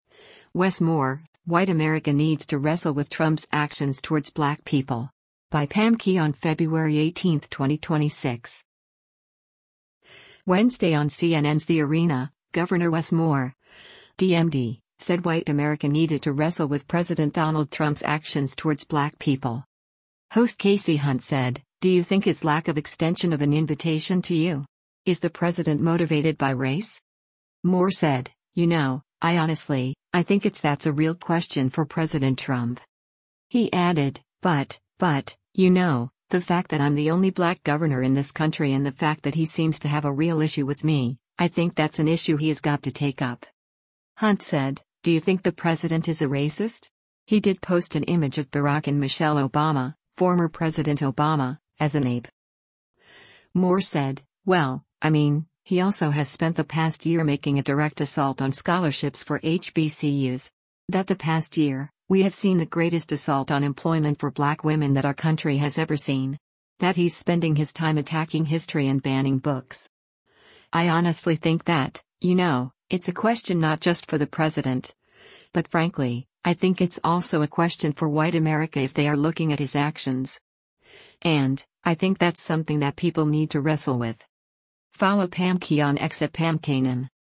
Host Kasie Hunt said, “Do you think his lack of extension of an invitation to you? Is the president motivated by race?”